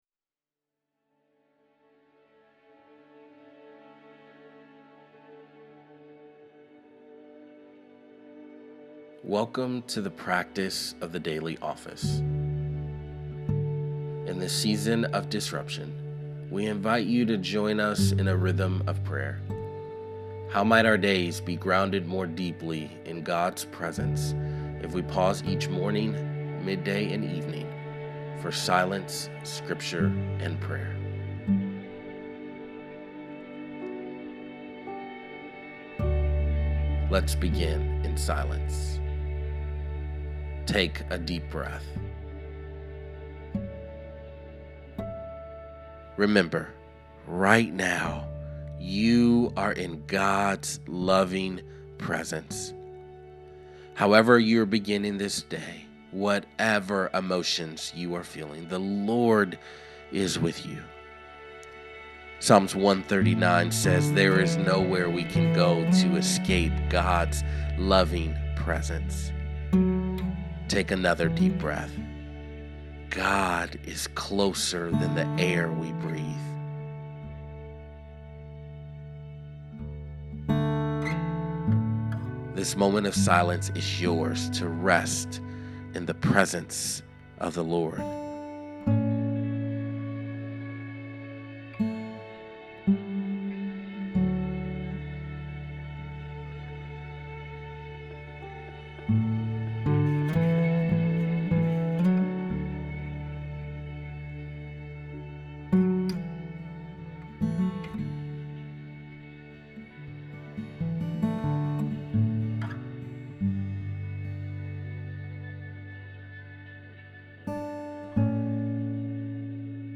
Below you will find a series of guided prayers.
Each prayer begins in silence. Then the morning prayer guides us in a practice of scripture called Lectio Divina.